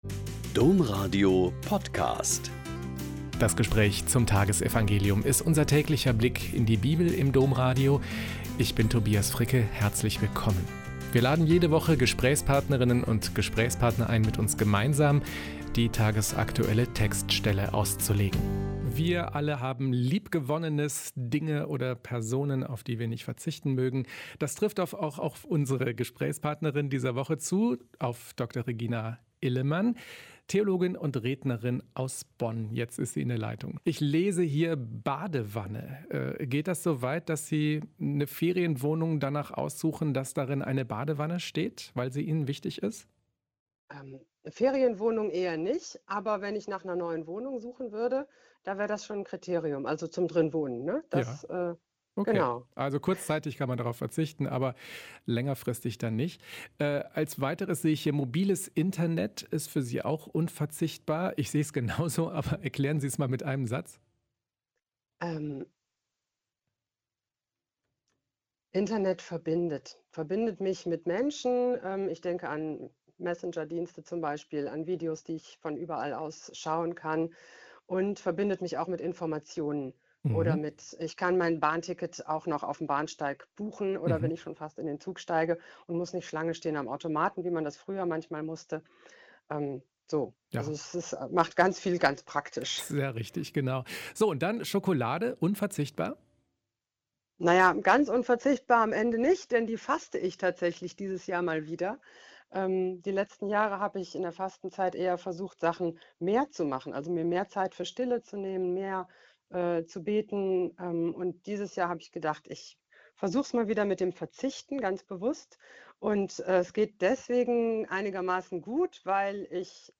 Mt 23,1-12 - Gespräch